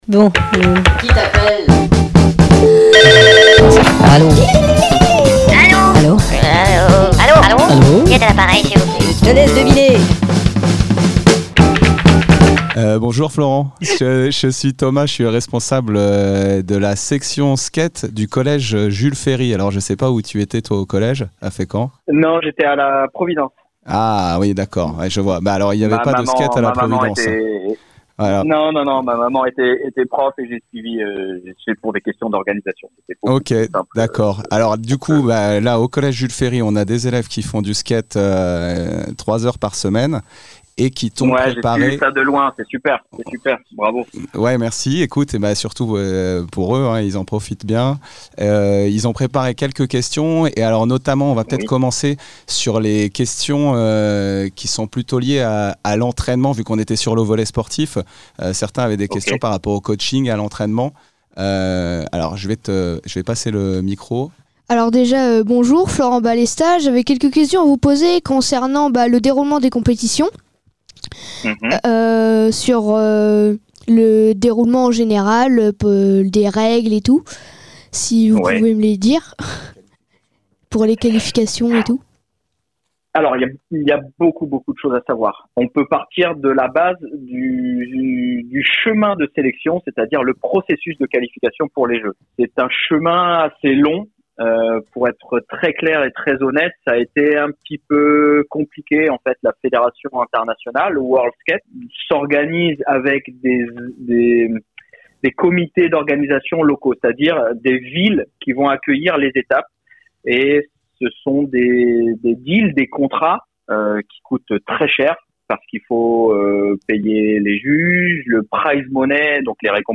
Pour mieux vous faire par de l'actu des artistes Normands, RADAR les appelle directement !